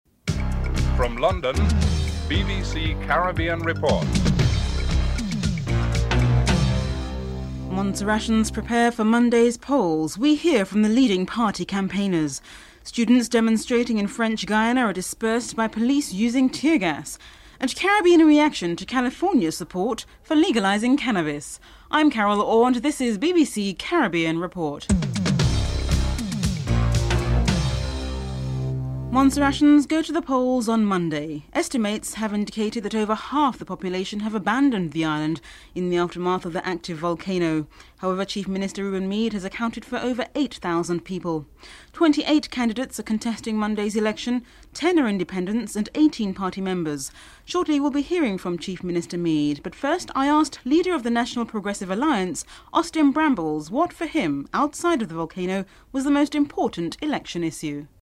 1. Headlines (00:00-00:28)
A journalist from ACJ Broadcasting Station reports (06:36-08:59)